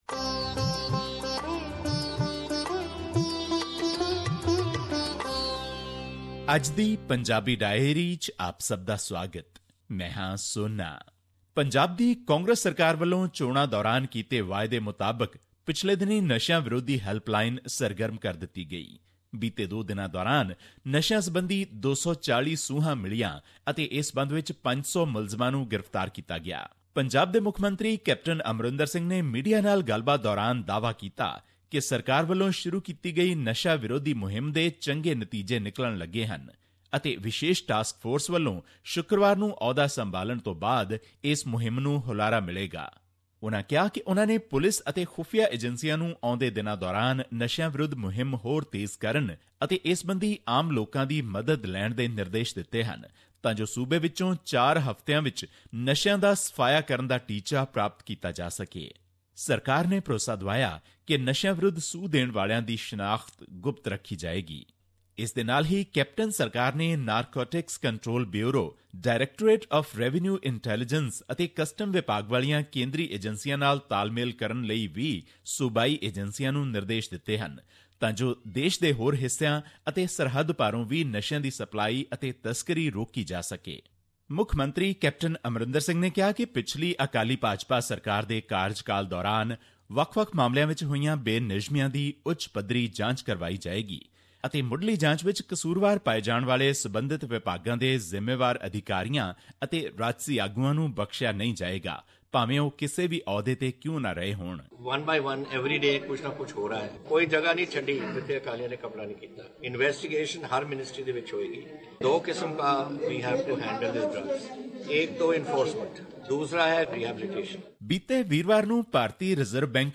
His report was presented on SBS Punjabi program on Monday, April 03, 2017, which touched upon issues of Punjabi and national significance in India.